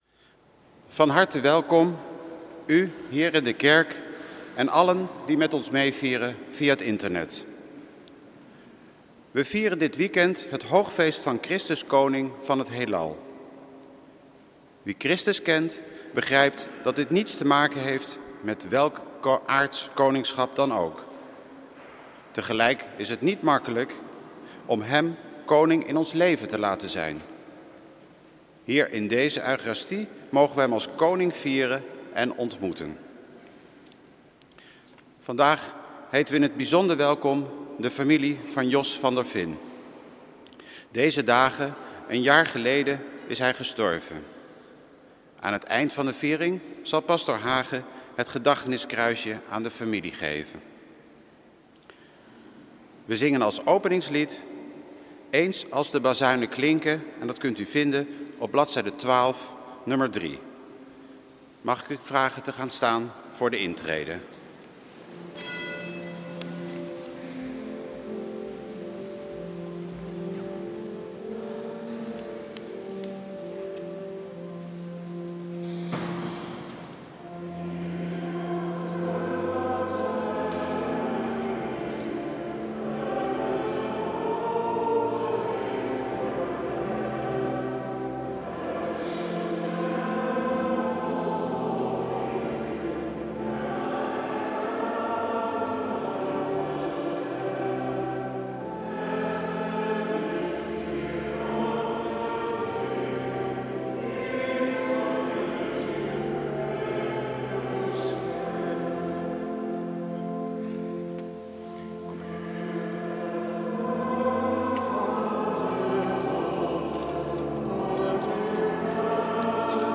Eucharistieviering beluisteren vanuit de Willibrorduskerk te Wassenaar (MP3)